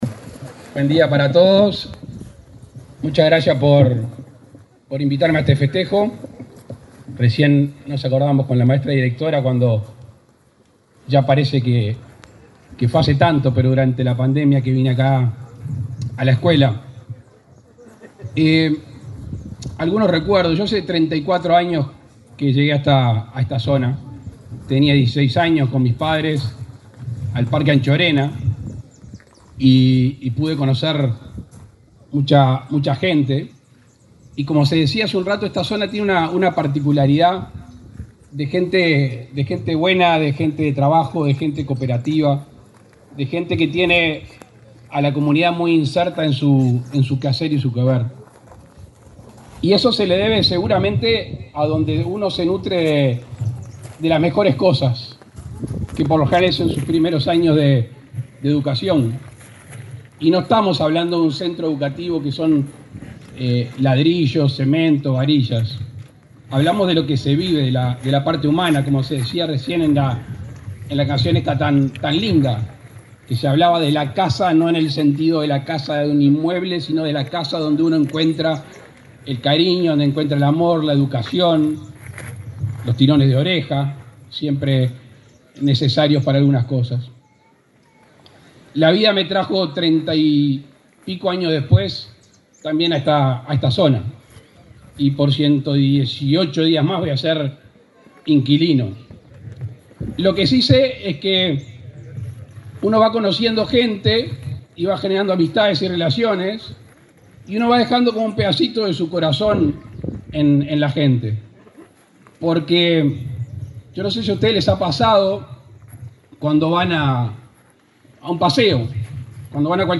Palabras del presidente Luis Lacalle Pou
Palabras del presidente Luis Lacalle Pou 03/11/2024 Compartir Facebook X Copiar enlace WhatsApp LinkedIn Este domingo 3, el presidente de la República, Luis Lacalle Pou, encabezó la ceremonia conmemorativa del 100.° aniversario de la escuela n.° 79, Aarón de Anchorena, en la localidad de Paso de la Horqueta, departamento de Colonia.